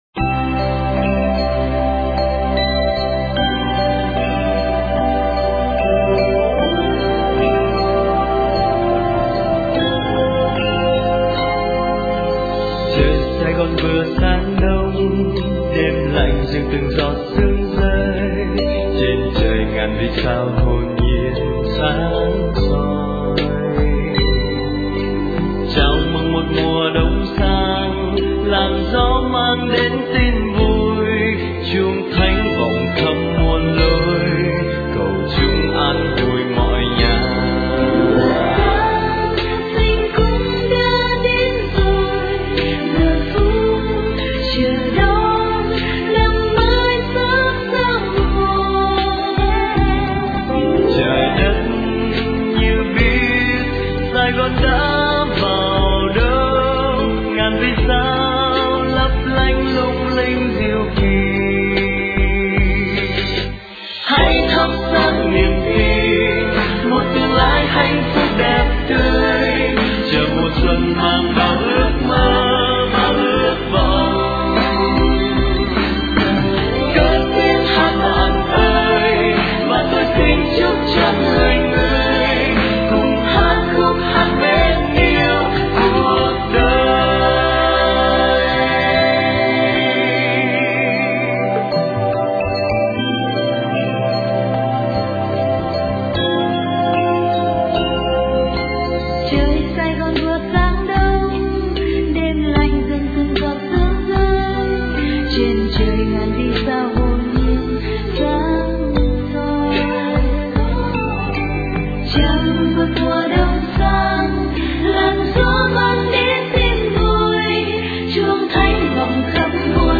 * Thể loại: Noel